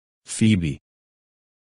Написание и аудио произношение – Spelling and Audio Pronunciation